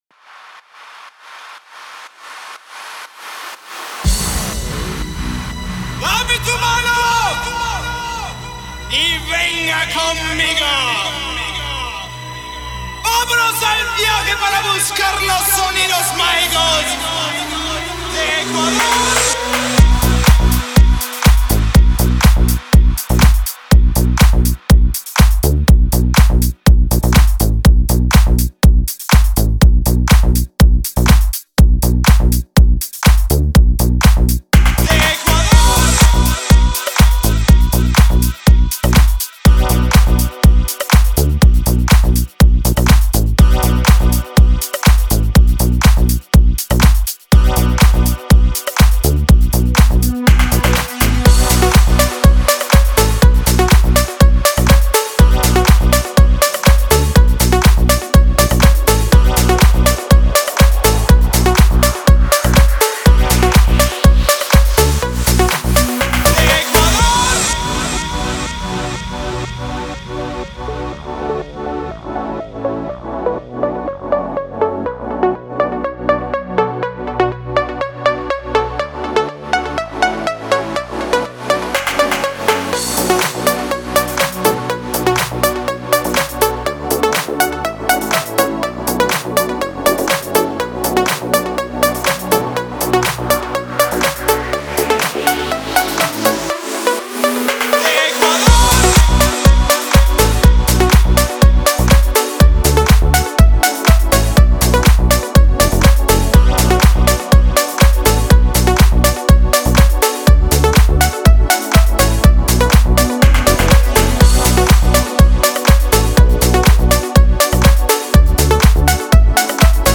Клубная музыка
ремиксы ретро песен